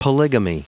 Prononciation du mot : polygamy